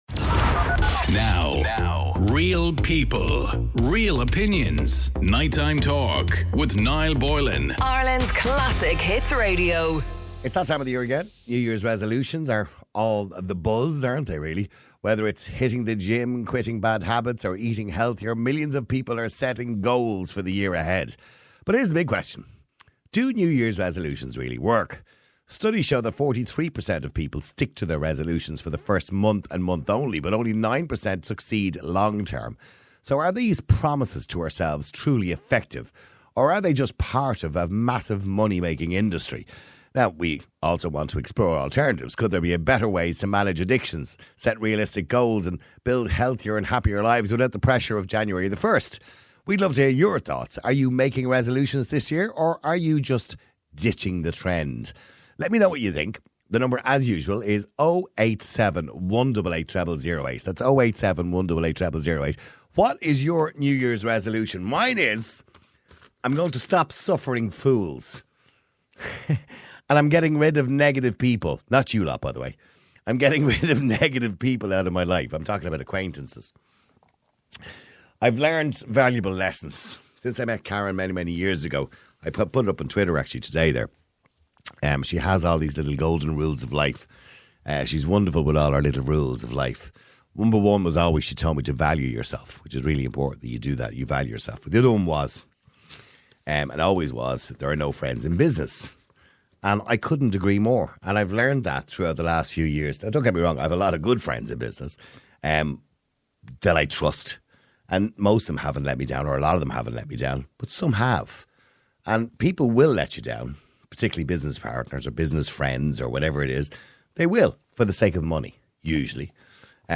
MIDNIGHT MONDAY TO THURSDAY ON IRELAND'S CLASSIC HITS RADIO … continue reading 861 episodi # News Talk # News # Radio Station